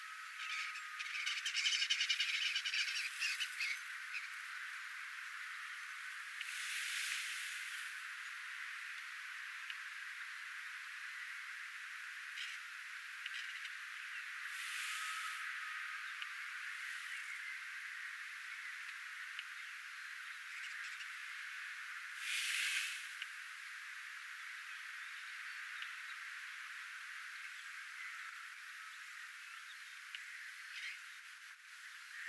kottaraisin yllättävää ääntelyä,
erikoista ääntä
Se on jotain kiistelyä tai jotain kevätkinastelya
yllattavaa_kottaraisen_aantelya_kinastelua.mp3